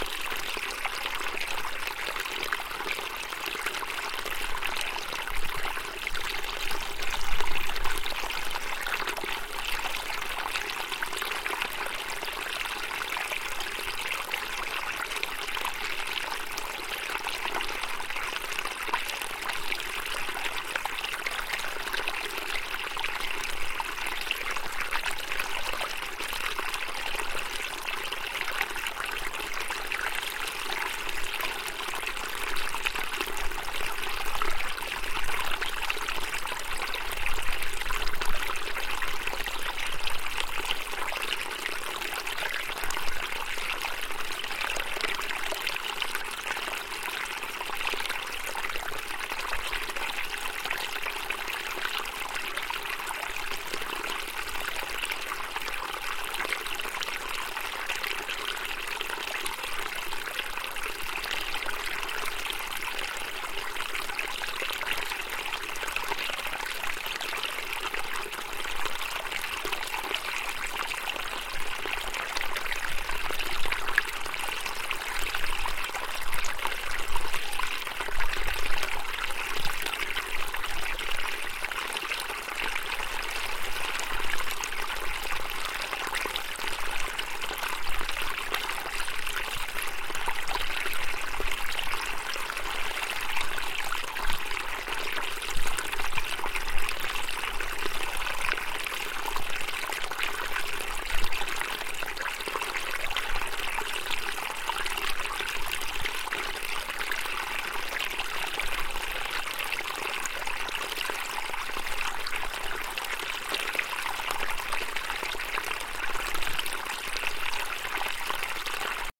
描述：这是我在俄勒冈州中部一座名为Broken Top的火山周围背包旅行几天时记录的一系列小溪中的一条。
编辑工作做得很少，只是进行了一些剪切，以消除处理噪音或风。用AT4021话筒录入改良的马兰士PMD661.
标签： 小溪 汩汩 环境 飞溅 小溪 流水声 液体 现场记录 放松 涓流
声道立体声